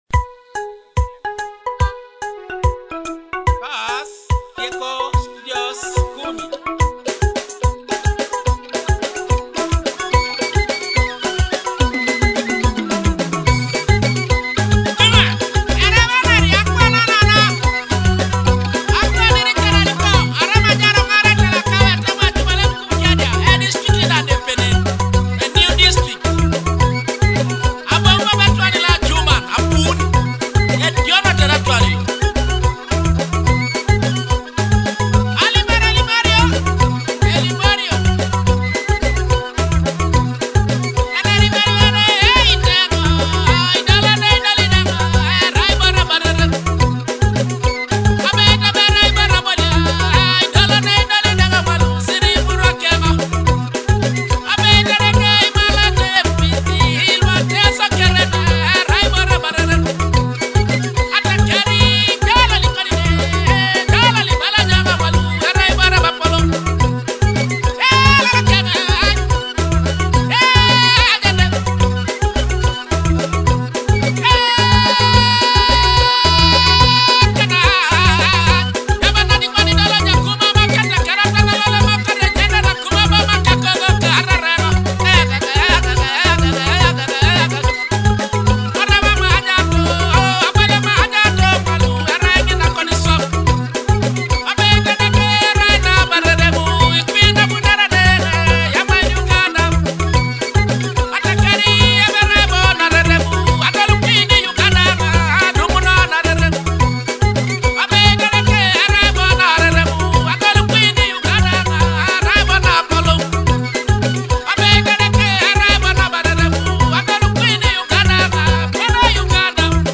Teso cultural and traditional rhythms